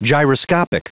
Prononciation du mot gyroscopic en anglais (fichier audio)
Prononciation du mot : gyroscopic
gyroscopic.wav